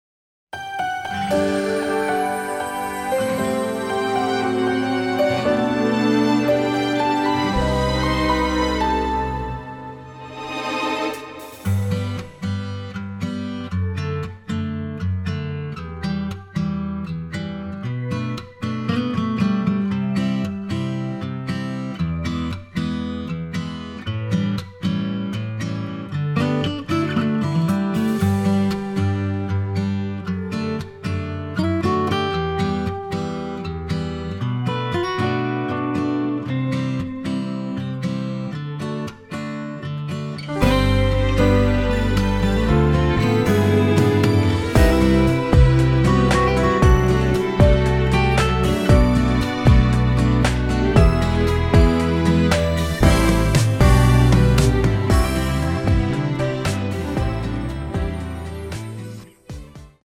앞부분30초, 뒷부분30초씩 편집해서 올려 드리고 있습니다.
공식 MR